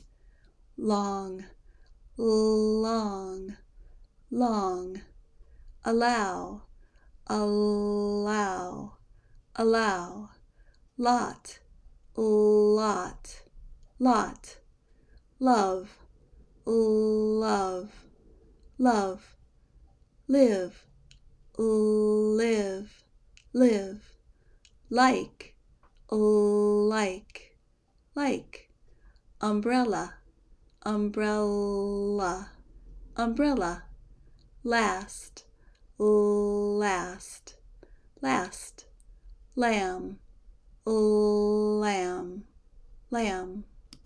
Pronounce L in American English
Practice these words with L
L-words.mp3